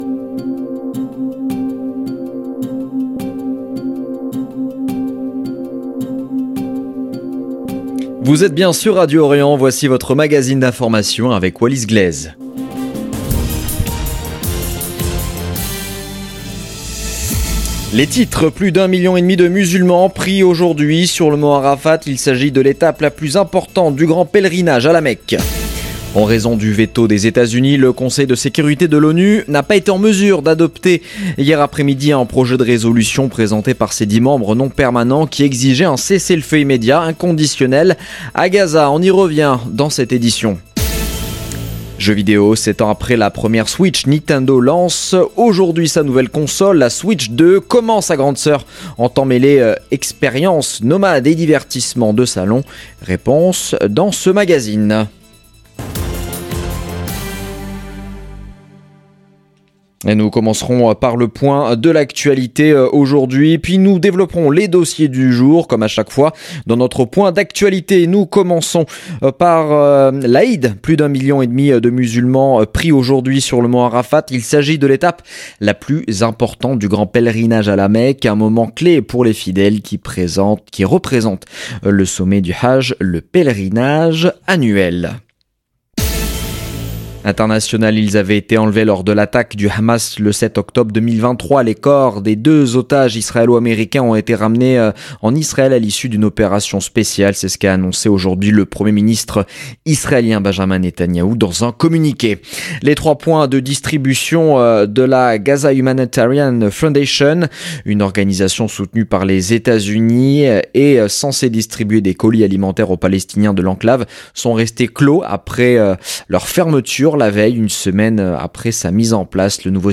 Magazine de l'information de 17H00 du jeudi 5 juin 2025